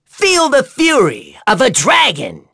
Neraxis-Vox_Skill4.wav